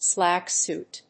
アクセントsláck sùit